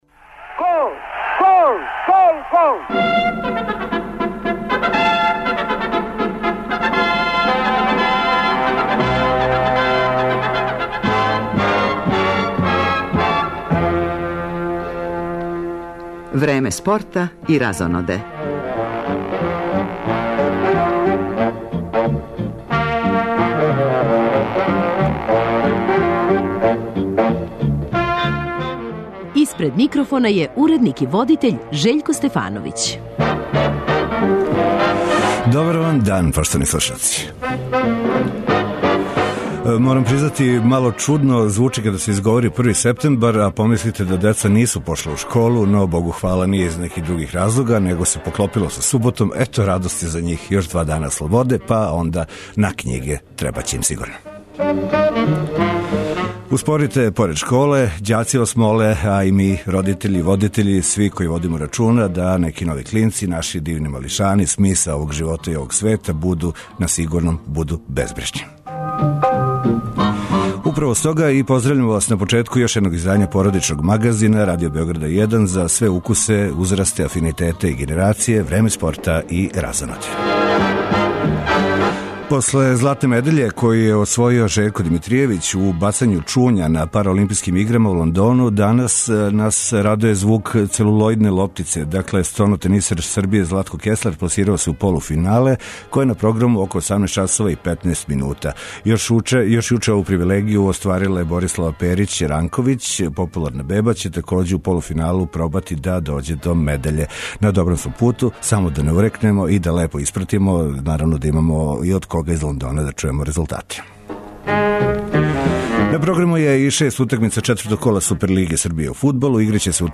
Породични радио магазин почињемо утисцима о фудбалском четвртку иза нас, после кога се Партизан нашао у групној фази Лиге Европе, а Звезда за ову сезону опростила од међународне сцене.
Ту су и укључења репортера са финалног бич-волеј такмичења на Ади Циганлији.